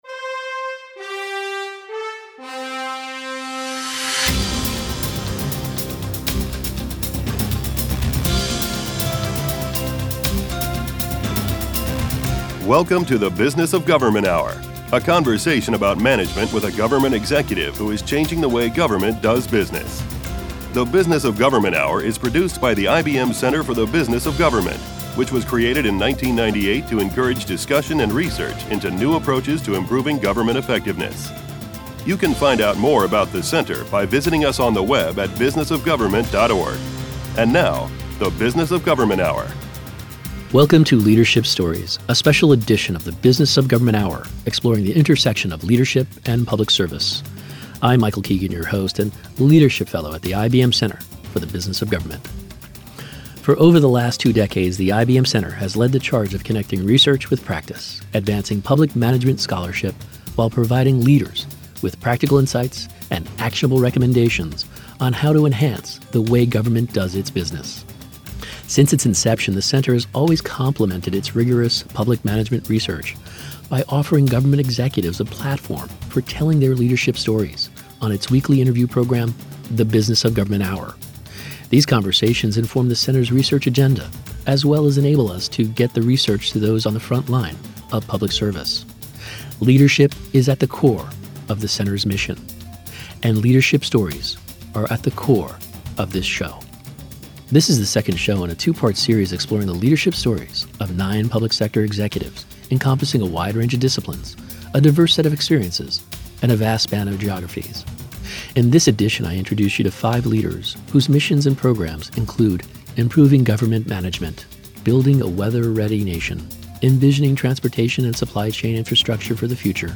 A Conversation with Leaders